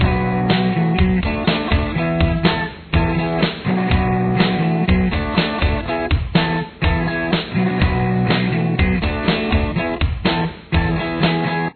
2nd Intro Riff
Below are the two rhythm guitar parts:
Here’s what it sounds like at regular tempo: